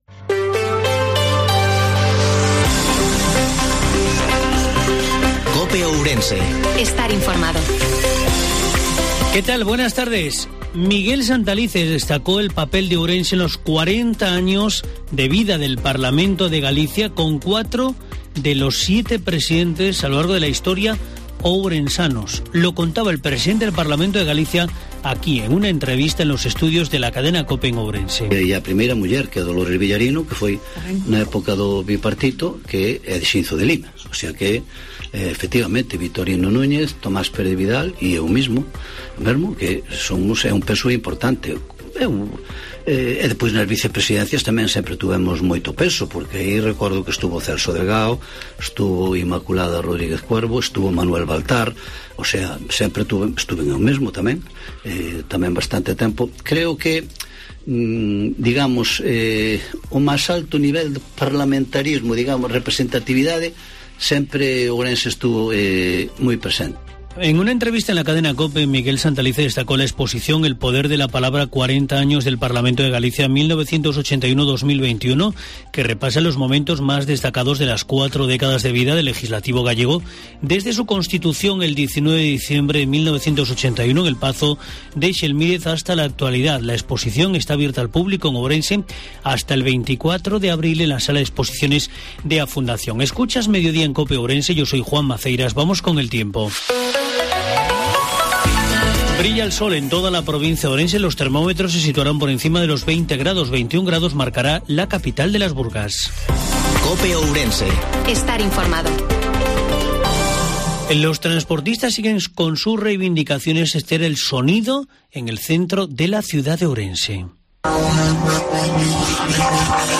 INFORMATIVO MEDIODIA COPE OURENSE 24/03/2022